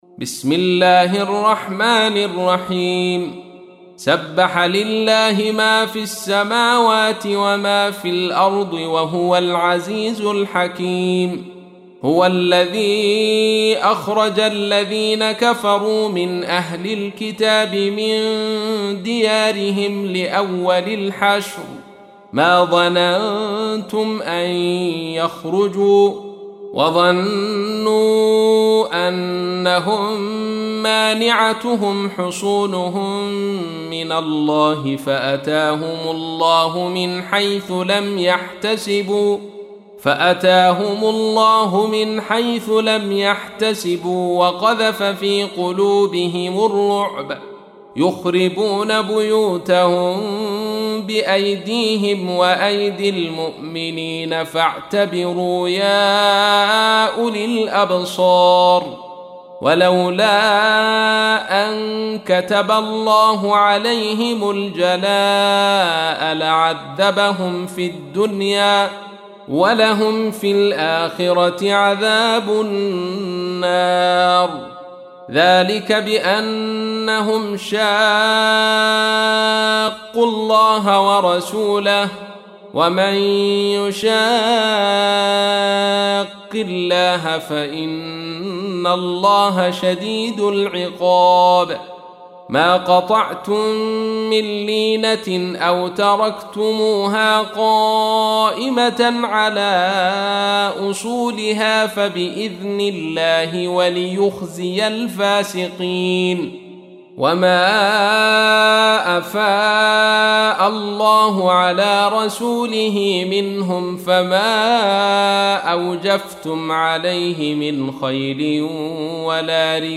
تحميل : 59. سورة الحشر / القارئ عبد الرشيد صوفي / القرآن الكريم / موقع يا حسين